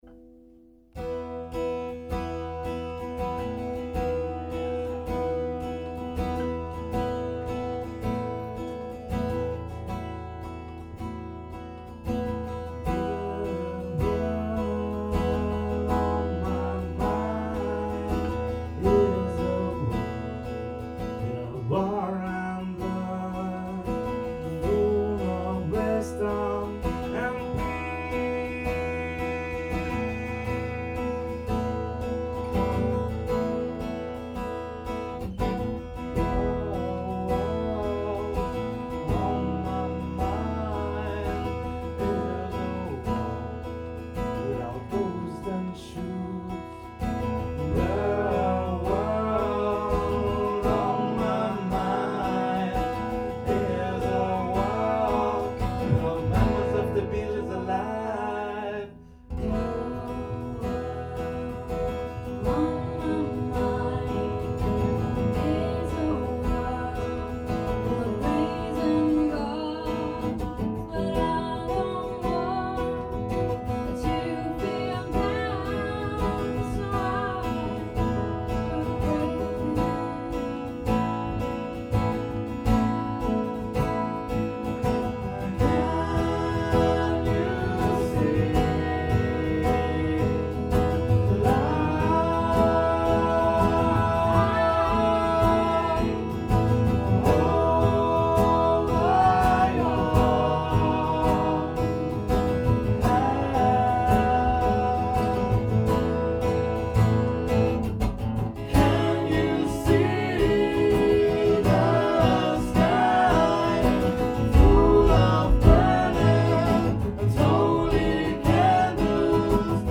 solo guitar
guitar/vocals
rap/saxophone